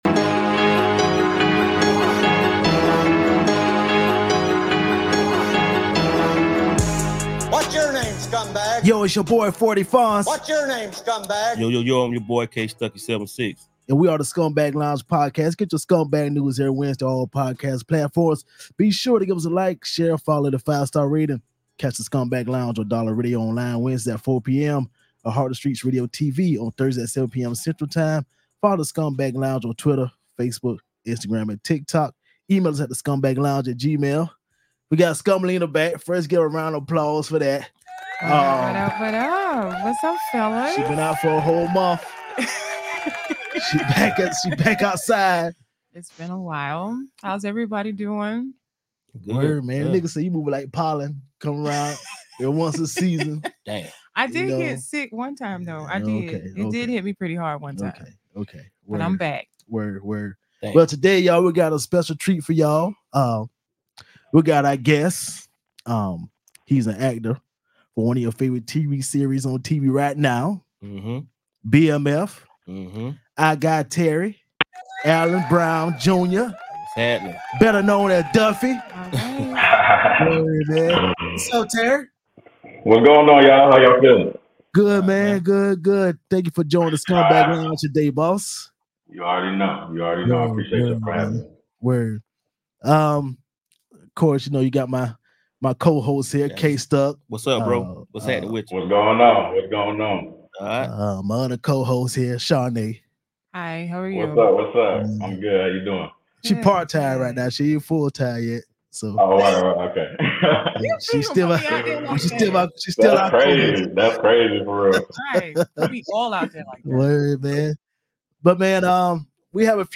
So tune in and enjoy the conversation.